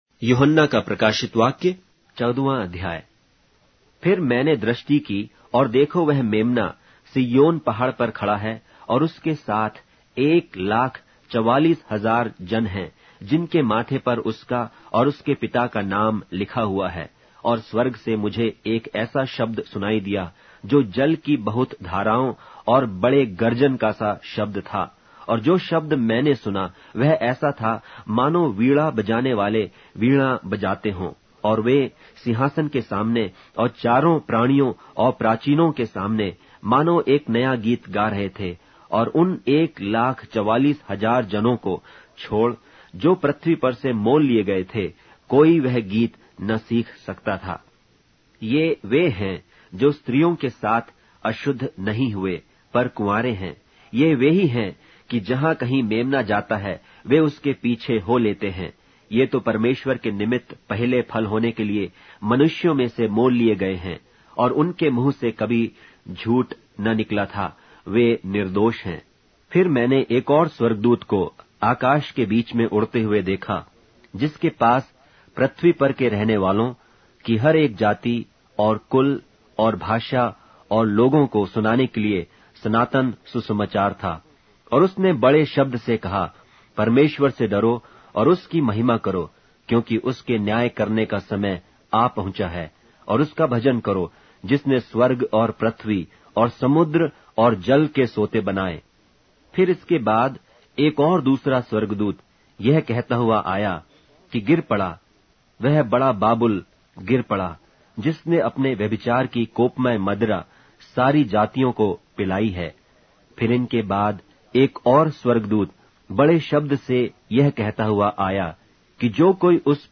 Hindi Audio Bible - Revelation 9 in Mkjv bible version